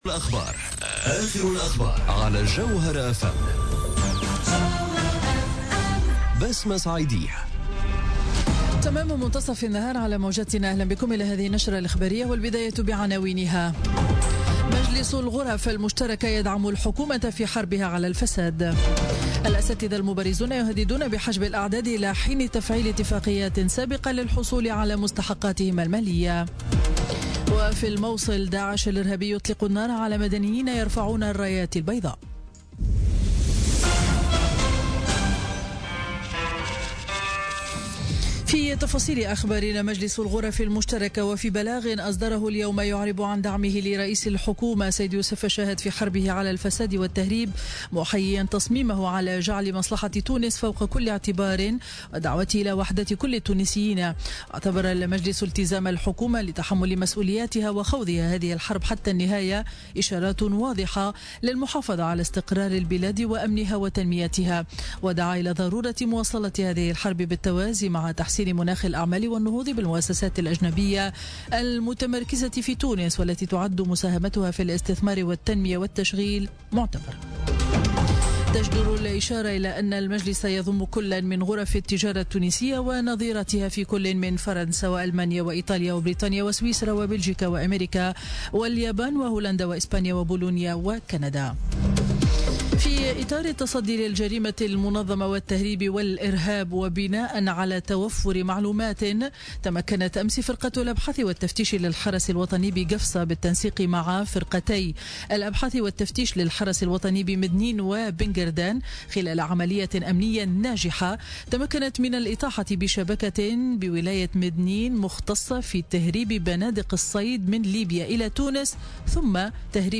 نشرة أخبار منتصف النهار ليوم الخميس 1 جوان 2017